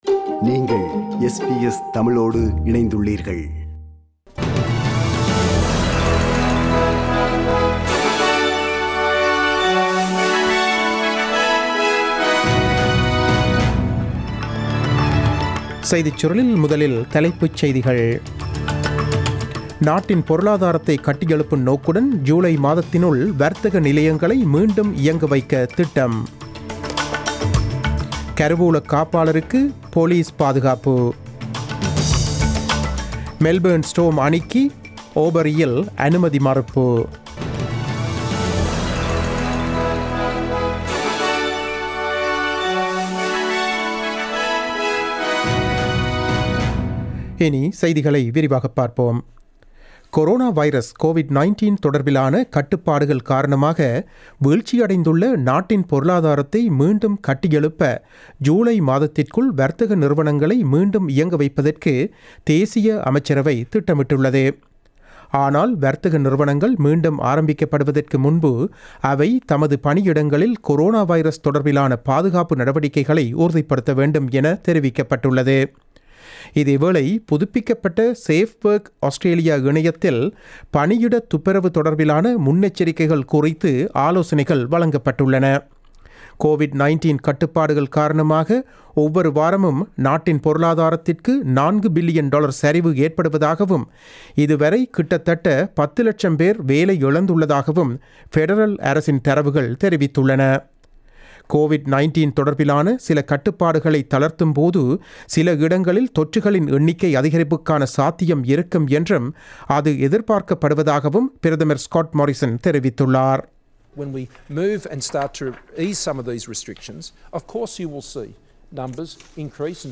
The news bulletin broadcasted on 06 May 2020 at 8pm.